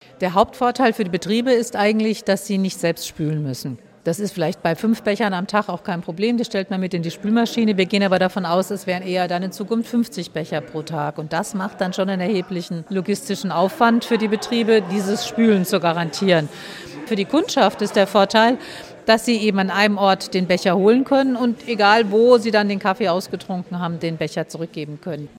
Freiburgs Umweltbürgermeisterin Christine Buchheit zu den Vorteilen des Mehrwegverbunds: